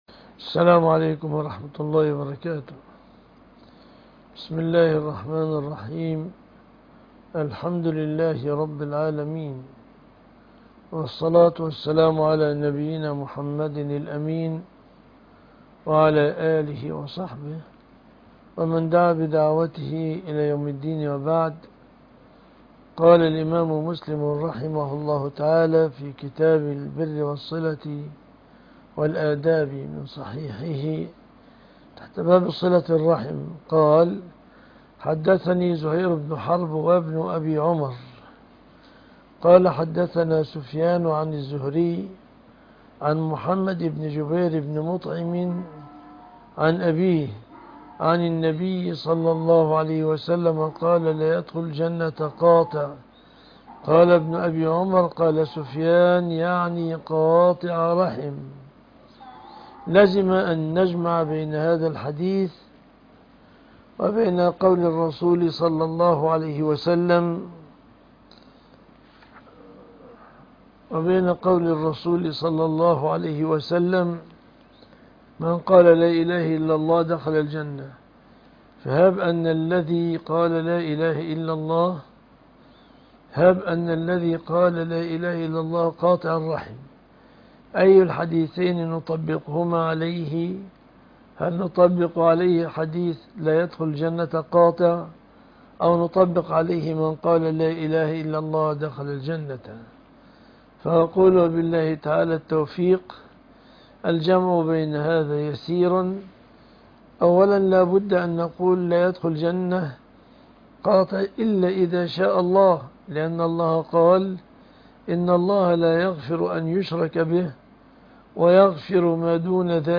الحديث وعلومه     شرح الأحاديث وبيان فقهها